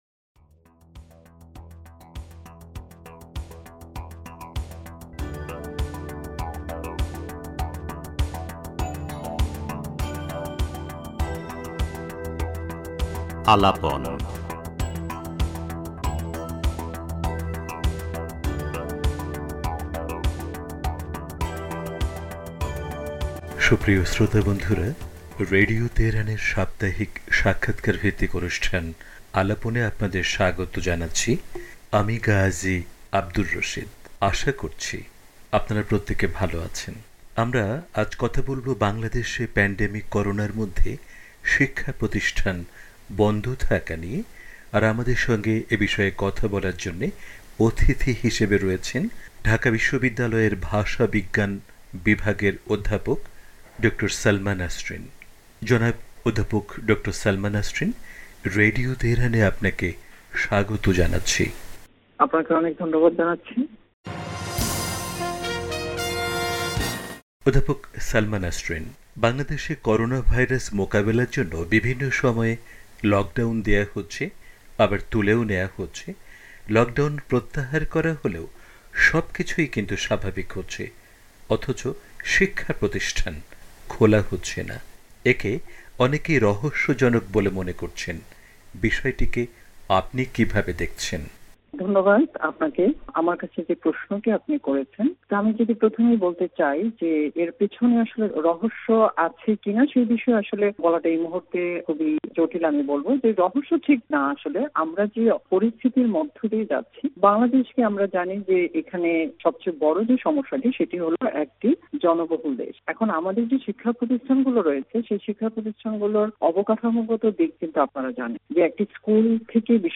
পুরো সাক্ষাৎকারটি তুলে ধরা হলো।